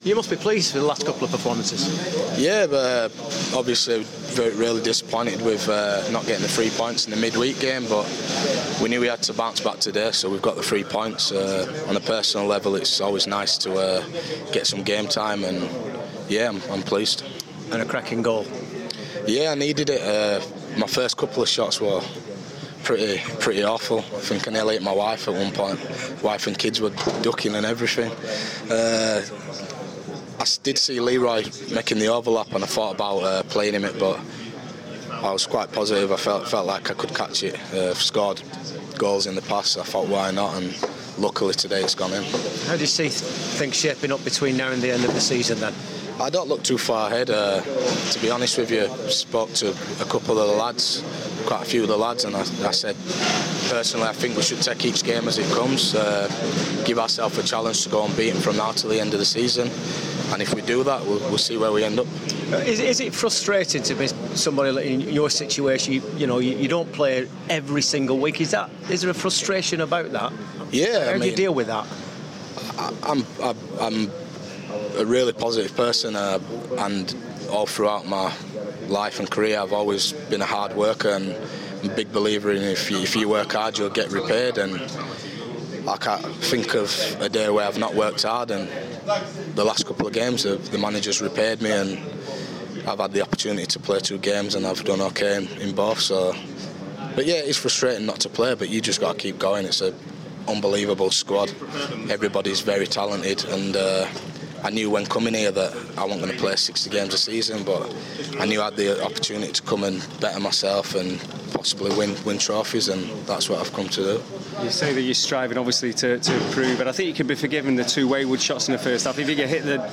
Goalscorer Fabien Delph talks following the 3-1 win over Hull at the Etihad.